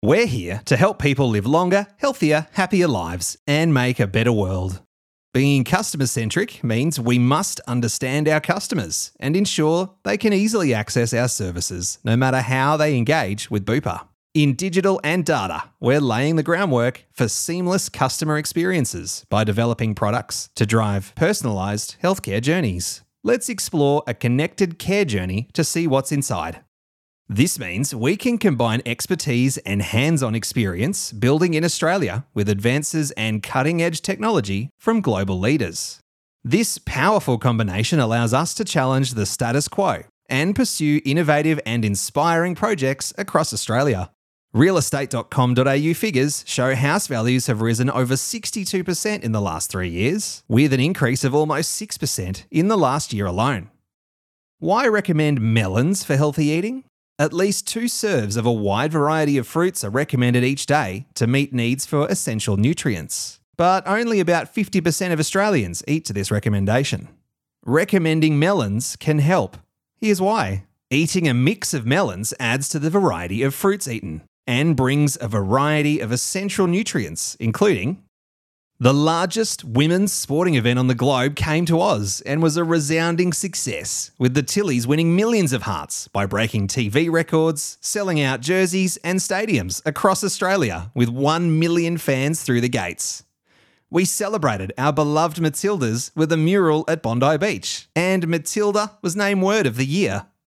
Male
Relatable, conversational male Australian voice.
Natural, friendly, warm, approachable, affable, every-man style Australian voice.
Corporate
Natural, Corporate Read